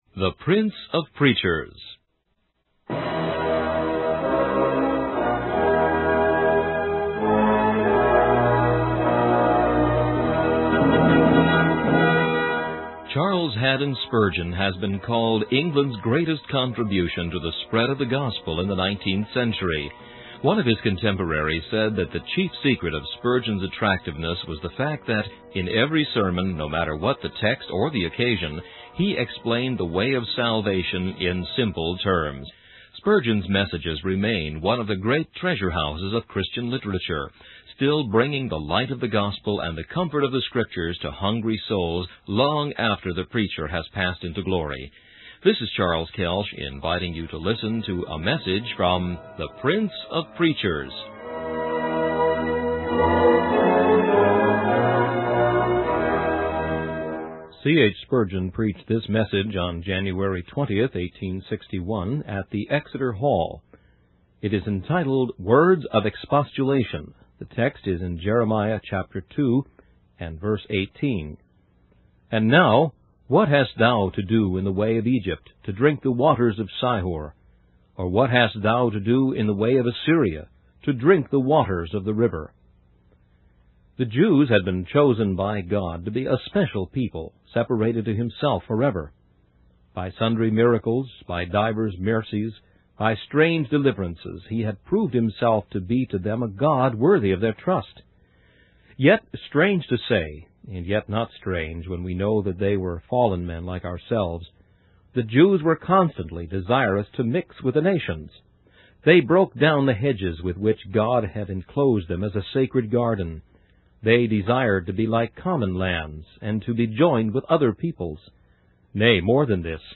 In this sermon, the preacher begins by describing a vision of a great feast where armies have met and there has been a terrible slaughter. He relates this vision to the spiritual battle between sin and righteousness.